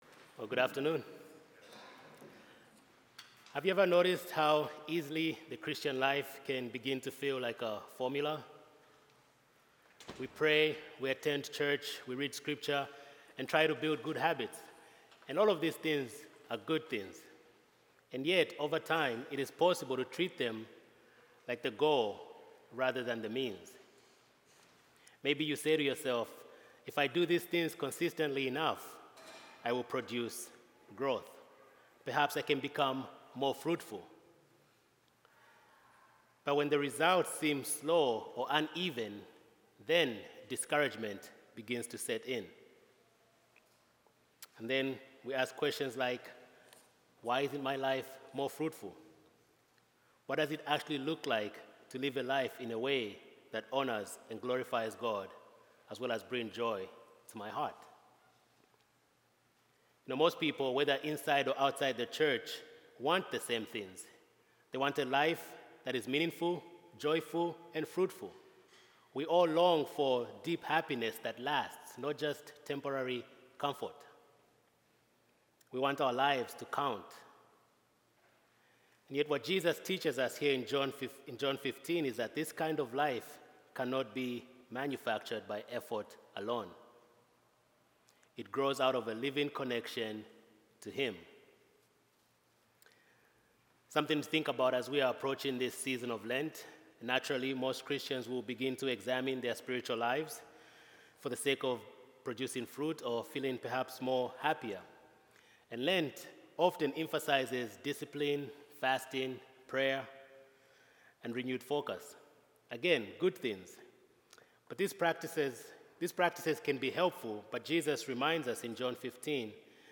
Standalone Sermon: In Christ Alone